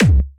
b_kick_v127l6o5c.ogg